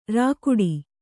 ♪ rākuḍi